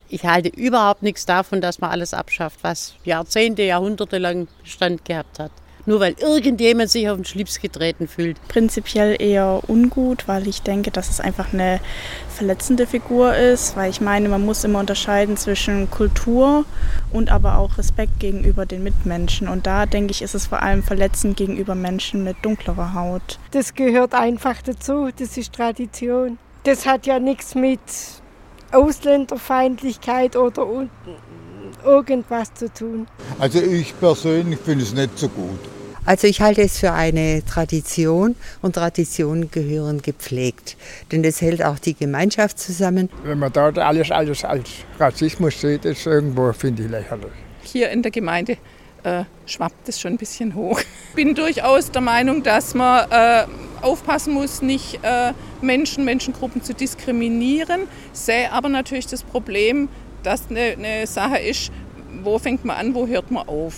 Bei der SWR-Umfrage auf den Straßen Wurmlingens wollten zwei Personen, die das Verhalten der Organisatoren kritisieren, nichts ins Mikrofon sagen.